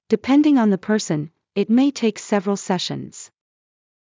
ﾃﾞｨﾍﾟﾝﾃﾞｨﾝｸﾞ ｵﾝ ｻﾞ ﾊﾟｰｿﾝ ｲｯﾄ ﾒｲ ﾃｲｸ ｾｳﾞｪﾗﾙ ｾｯｼｮﾝｽﾞ